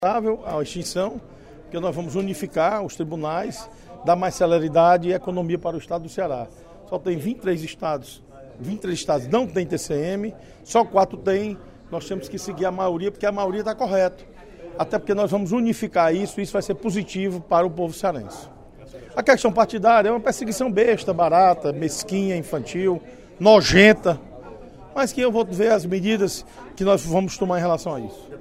O deputado Osmar Baquit  defendeu, durante o primeiro expediente da sessão plenária da Assembleia Legislativa desta terça-feira (11/07), a proposta de emenda constitucional (PEC) que extingue o Tribunal de Contas dos Municípios (TCM), em tramitação na Casa.